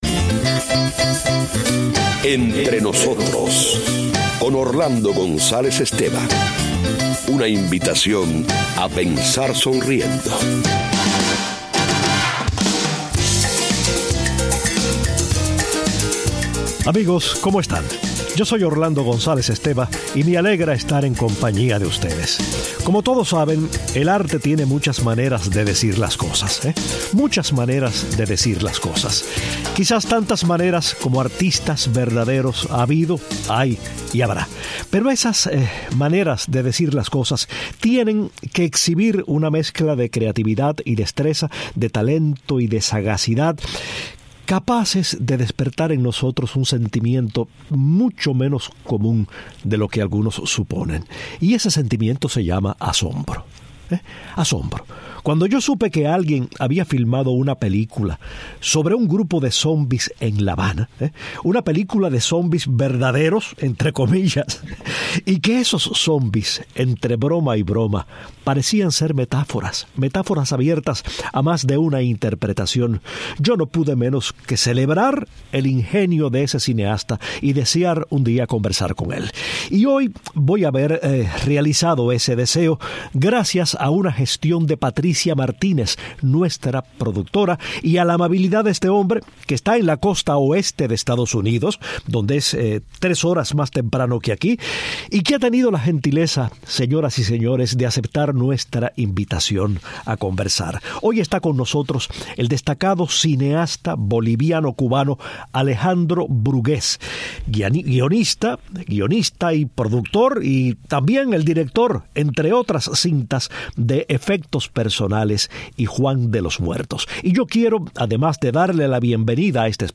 Entrevista a Alejandro Brugués